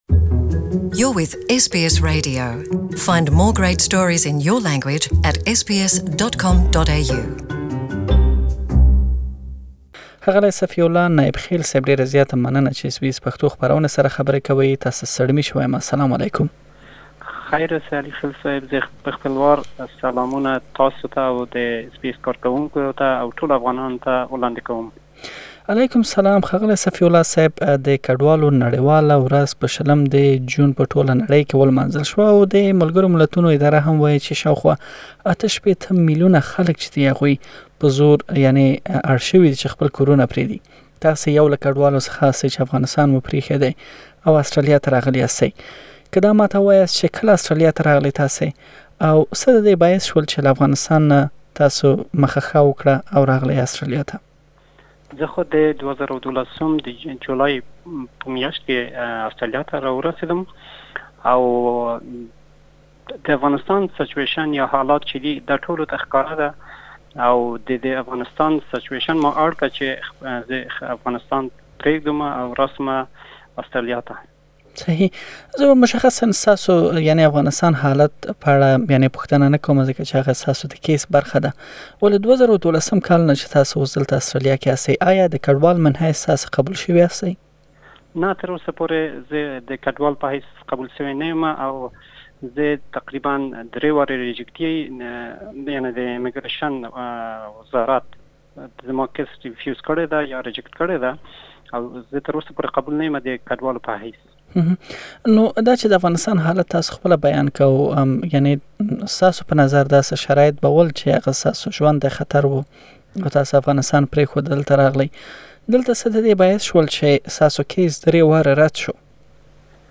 له ښاغلي سره مو مرکه کړې او د هغه سفر څخه نيولې اوسنۍ حالت مو هم راخيستی